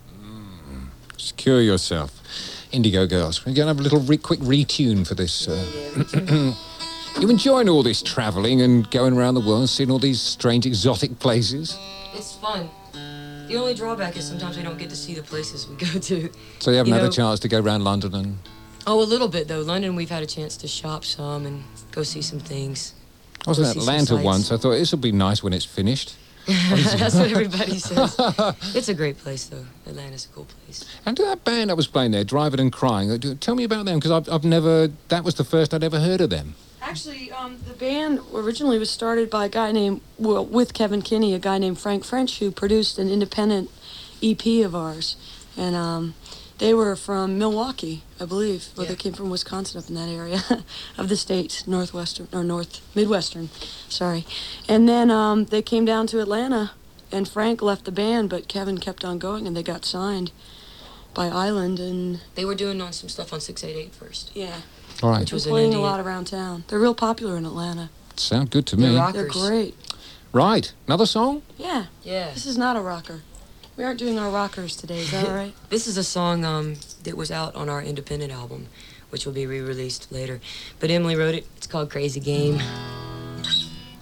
lifeblood: bootlegs: 1989-07-01: radio one - london, england
07. interview (1:24)